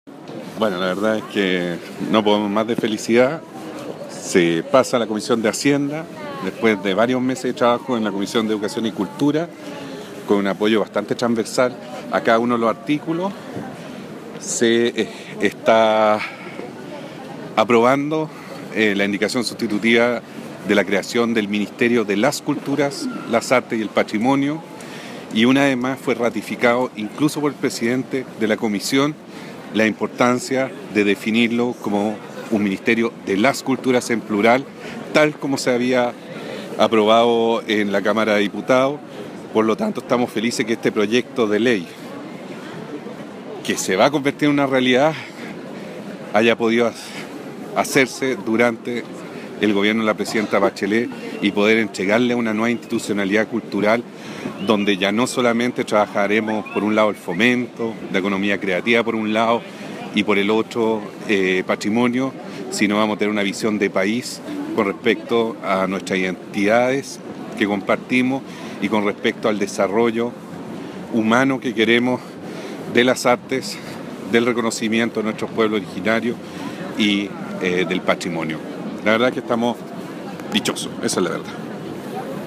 ministro-de-cultura-ernesto-ottone-por-proyecto-ministerio.mp3